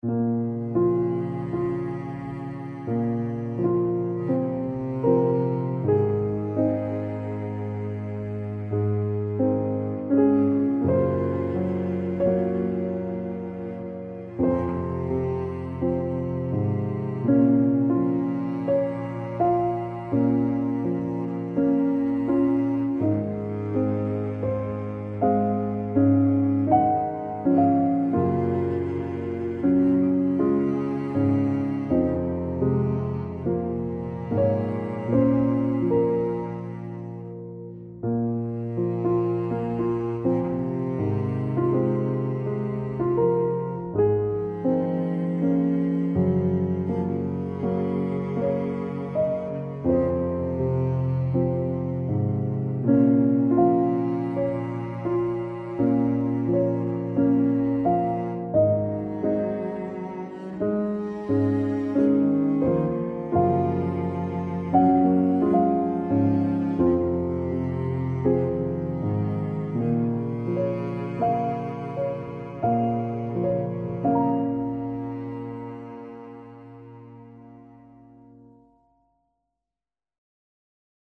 piano - triste - romantique - melancolique - tristesse